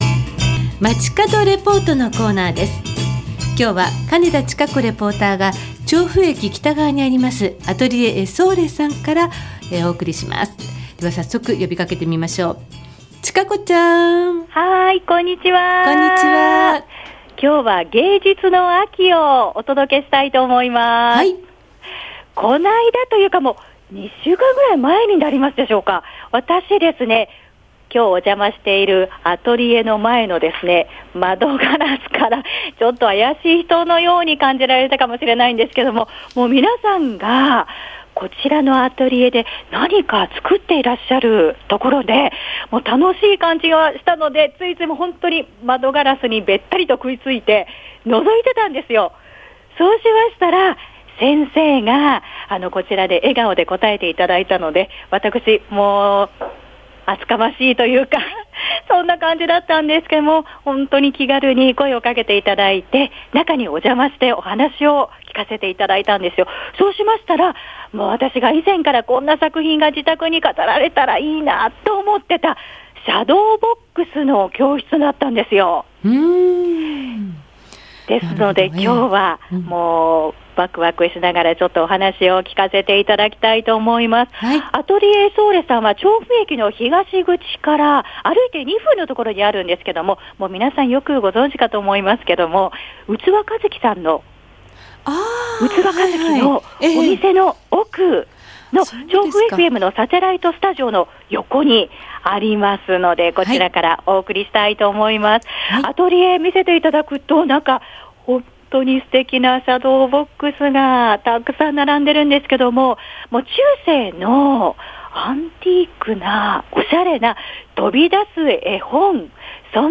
月曜日の街角レポート＜アトリエ エソーレ＞ 芸術の秋を堪能～今日は、３Dアート！！